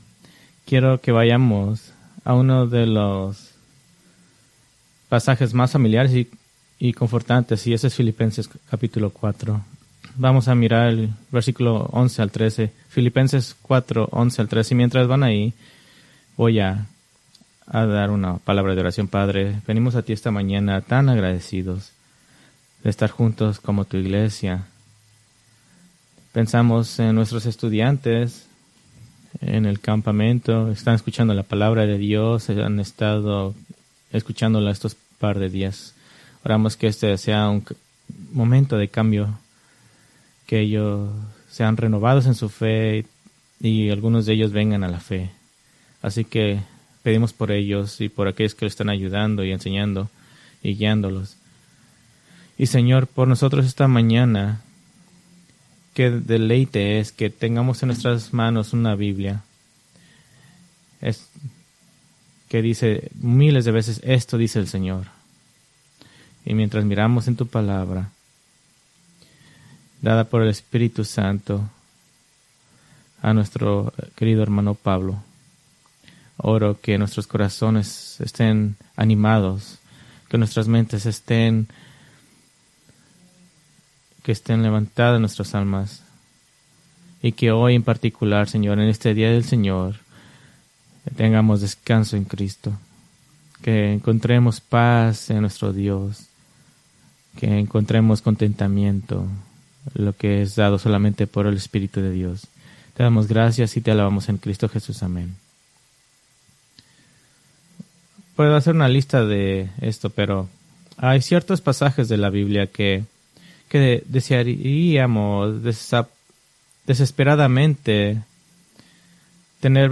Preached June 30, 2024 from Filipenses 4:11-13